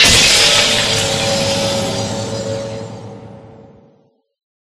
Magic3.ogg